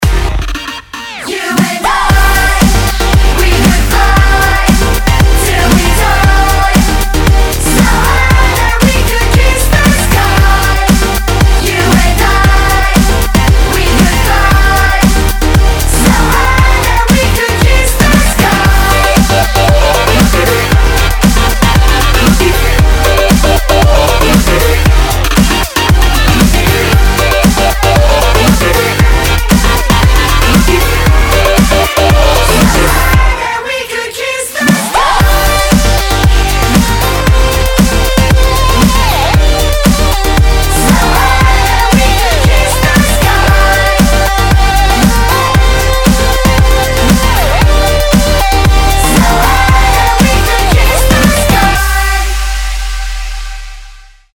Kategória: Dubstep